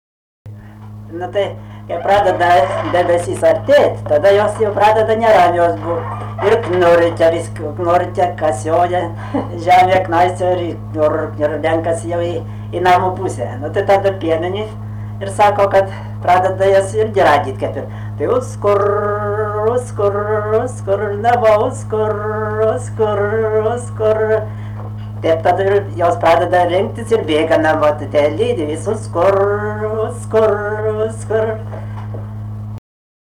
smulkieji žanrai
Kupiškis
vokalinis